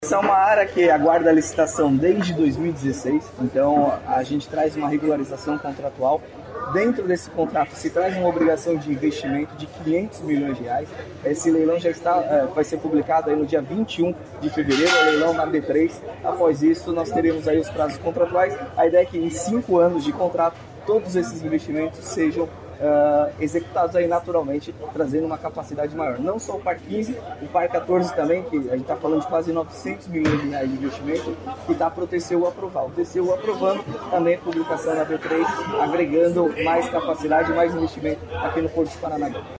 Sonora do diretor-presidente da Portos do Paraná, Luiz Fernando Garcia, sobre o edital de arrendamento da área PAR15